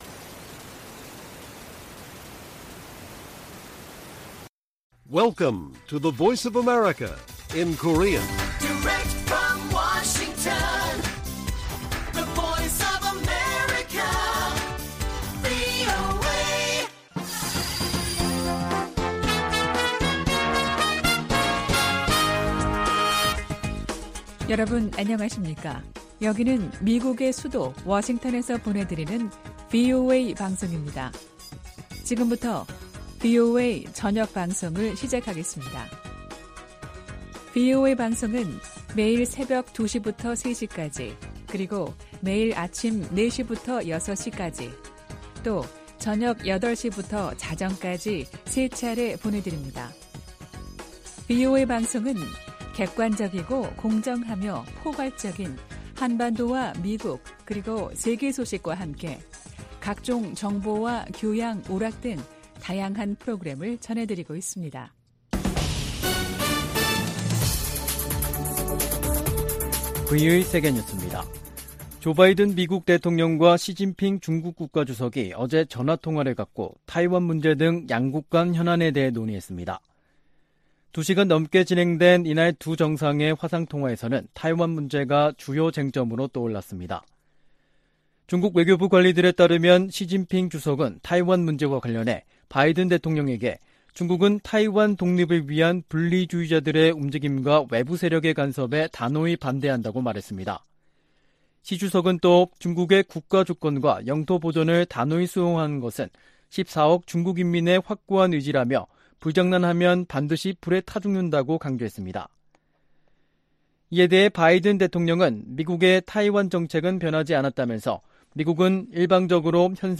VOA 한국어 간판 뉴스 프로그램 '뉴스 투데이', 2022년 7월 29일 1부 방송입니다. 미 국무부는 김정은 국무위원장의 전승절 기념행사 연설에 직접 반응은 내지 않겠다면서도 북한을 거듭 국제평화와 안보에 위협으로 규정했습니다. 핵확산금지조약(NPT) 평가회의에서 북한 핵 문제가 두 번째 주부터 다뤄질 것이라고 유엔 군축실이 밝혔습니다. 백악관 고위 관리가 북한이 미사일 자금 3분의 1을 사이버 활동으로 마련한다며 대응을 강화할 것이라고 말했습니다.